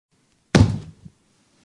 stamp.mp3